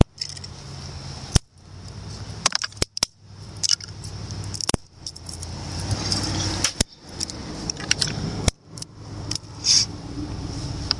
FX Twigs
描述：树枝的声音在一只脚下被折断和拖拽。